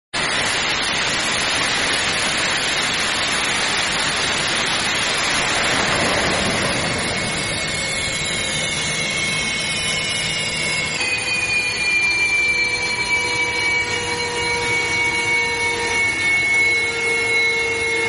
Semua Su 30MKM Yang Telah Melakukan Maintainance Enjin Perlu Diperiksa & Buat Afterburner Test Sebelum Pihak ATSC Menyerahkan Kepada TUDM Untuk Operasi Terbang...